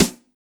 BEAT SD 06.WAV